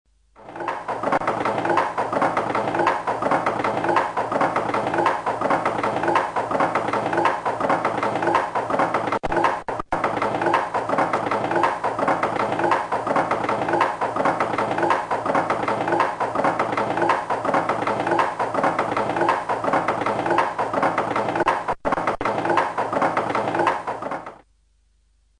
Matraca de cuatro aldabas
Allí donde alcanza la parte más gruesa del tirador, se colocan cuatro clavos (dos en un extremo y dos en el otro) donde golpeará dicho tirador cuando la tabla sea movida por el ejecutante.
Que la matraca fue un instrumento muy popular lo demuestra el hecho de aparecer como pieza antigua en muchos tratados sin ser propiamente un instrumento musical (al menos no hacía melodía, sino ruido).